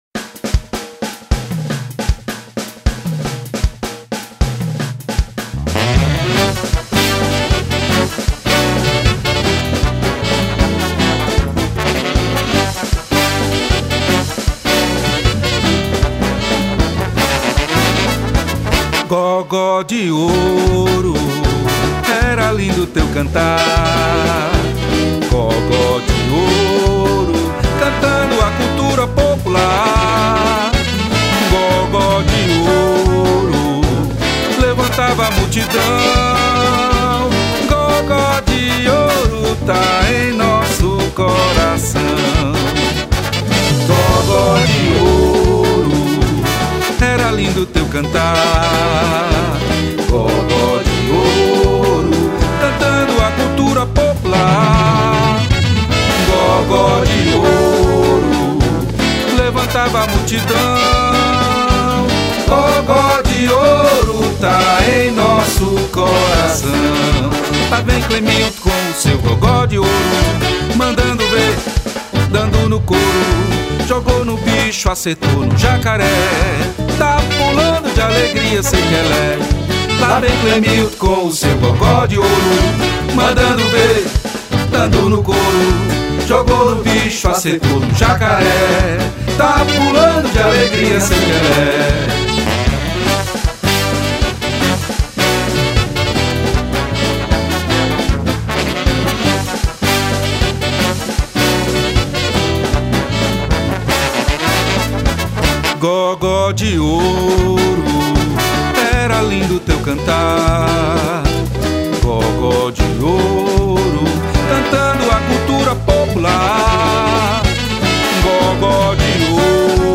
598   03:13:00   Faixa:     Frevo